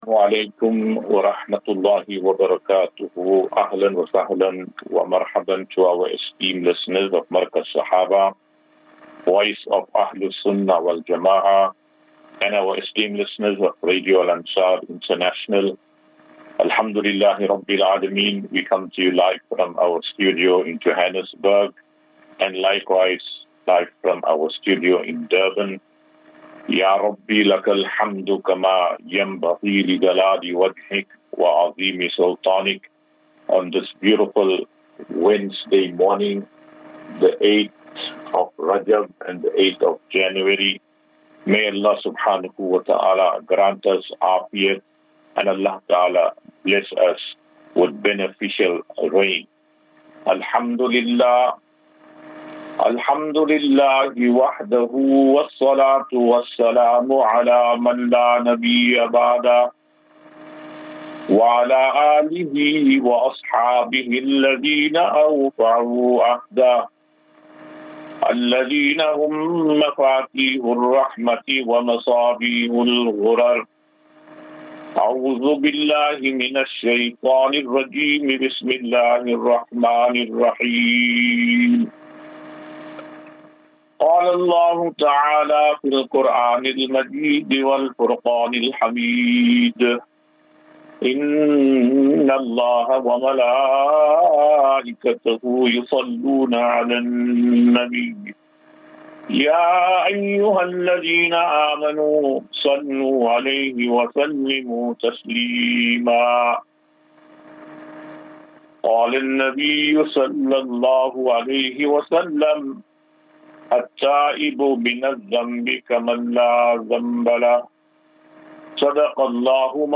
As Safinatu Ilal Jannah Naseeha and Q and A 8 Jan 08 January 2025.